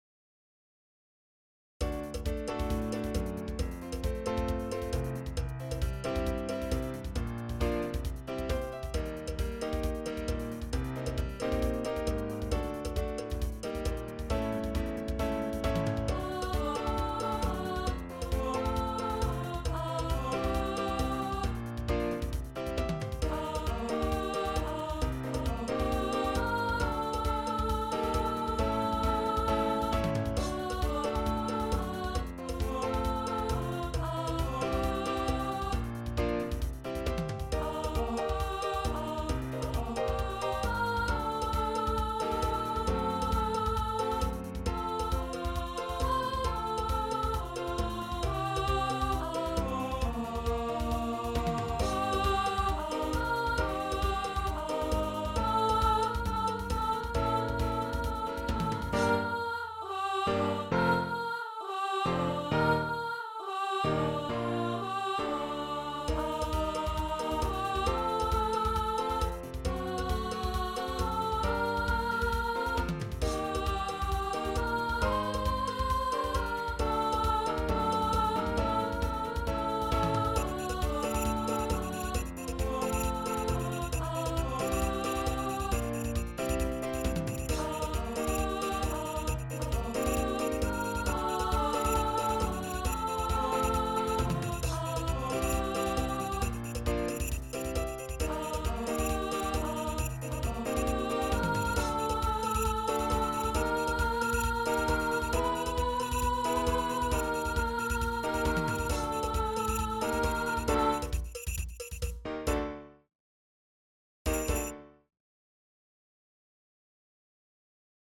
Unison / piano, percussion
Easy (congregational)